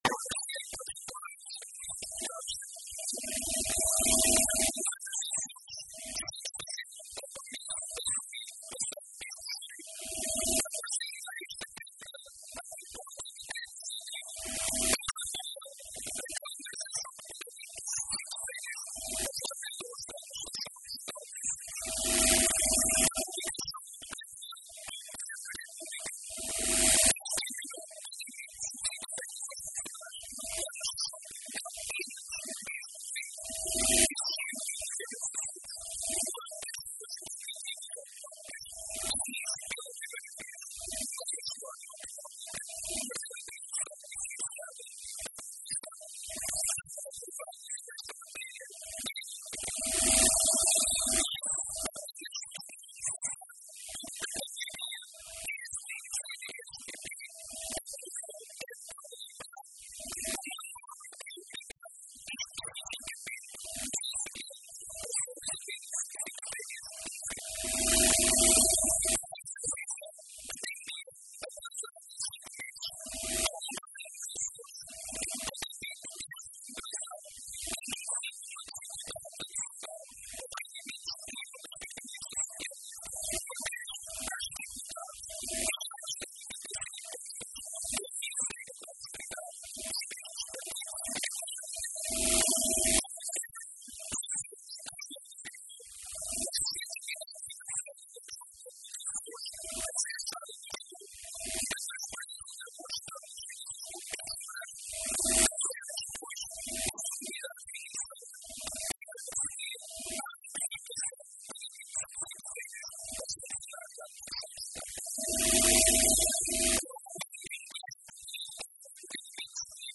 Intervenção do Vice-Presidente do Governo Regional
Texto integral da intervenção do Vice-Presidente do Governo Regional, Sérgio Ávila, proferida hoje na Assembleia Legislativa da Região Autónoma dos Açores, na Horta, sobre o decreto que regula o pagamento do subsídio de férias aos funcionários da Administração Regional: